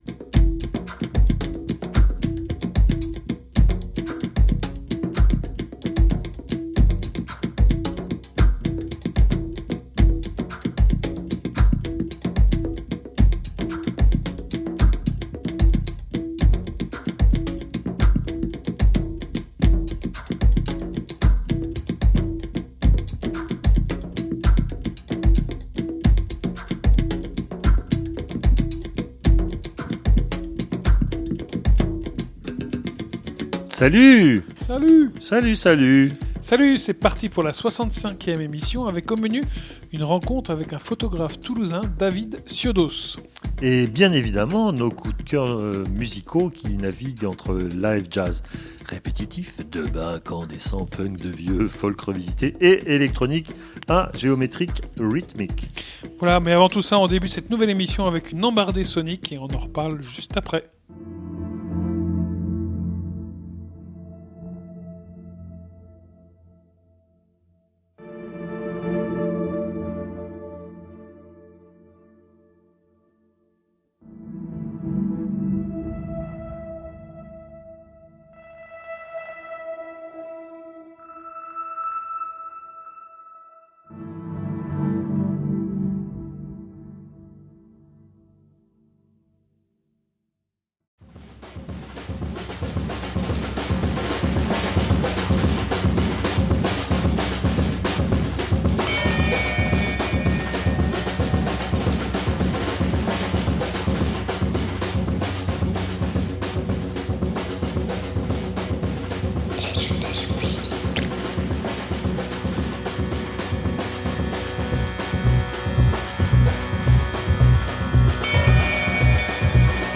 Création sonore “D.I.Y.” lors de cette joyeuse période dite du “confinement” en 2020.
On vous propose un nouveau montage d’une grande partie de ces dons sonores.
Les ÉDITIONS CRITIQUES sont une maison d’édition indépendante spécialisée en sciences humaines et sociales. Un entretien enregistré à Carcassonne, le 21 juin 2023 au calme, à la maison.